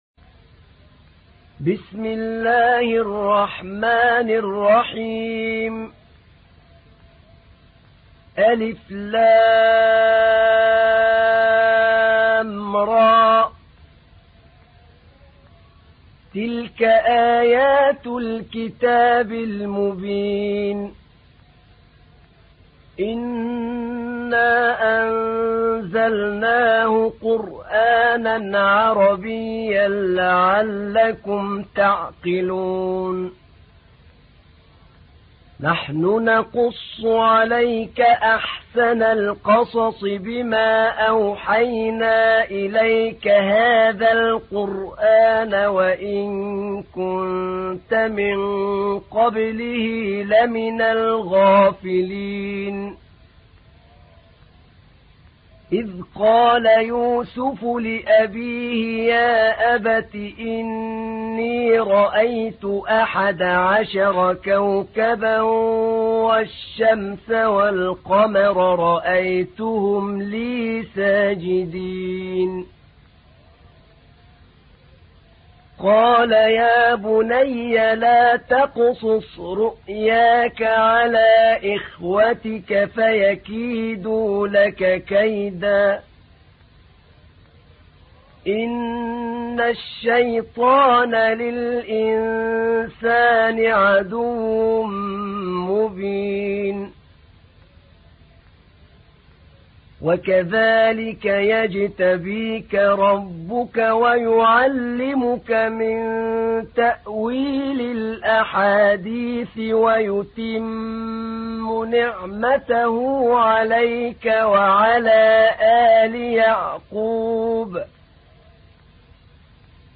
تحميل : 12. سورة يوسف / القارئ أحمد نعينع / القرآن الكريم / موقع يا حسين